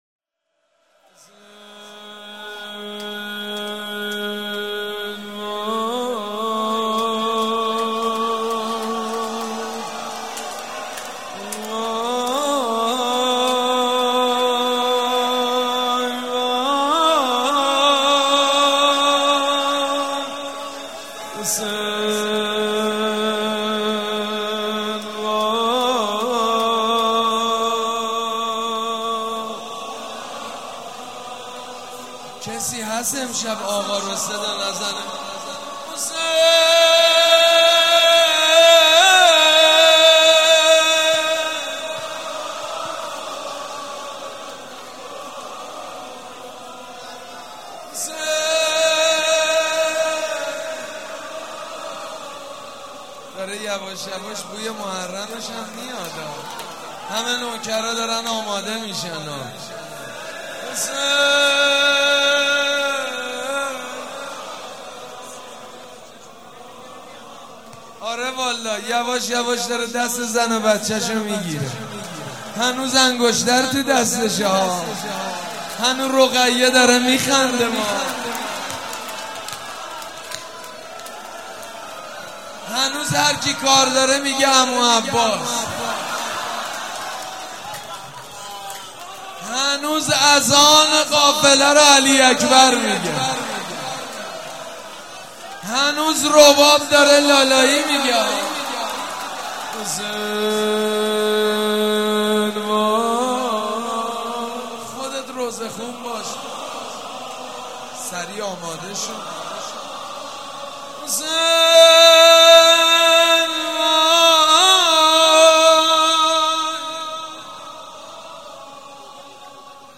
نغمه خوانی
مداح
حاج سید مجید بنی فاطمه
شهادت امام جواد (ع)